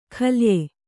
♪ khalye